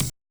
kick-hat.wav